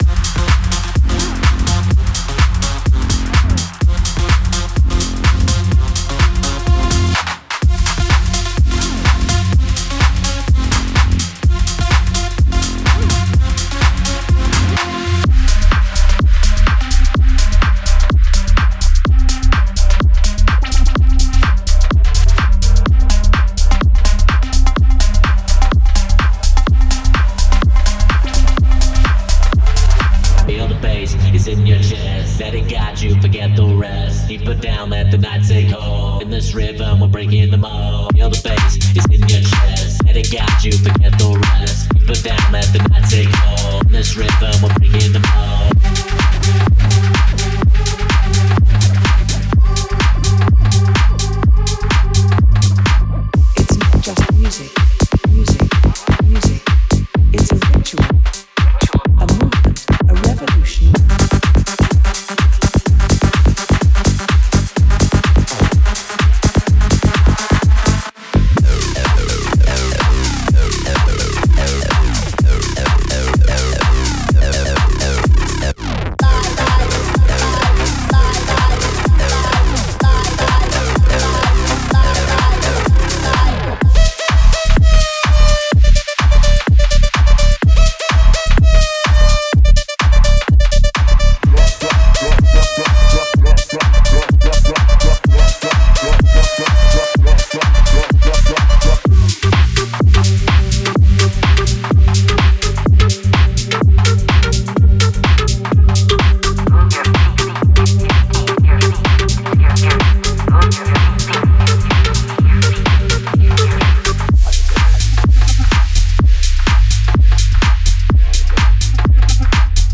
包含冲击力十足的鼓组、带电的合成器音色，以及粗粝且震撼身体的贝斯线条，完美捕捉了巨型 Drop 与不间断律动的核心精髓。
低频爱好者一定会爱上厚重的贝斯循环，完美穿透混音并震撼低音系统。
每一个元素都设计得强劲有力、节奏迅猛、声场宏大。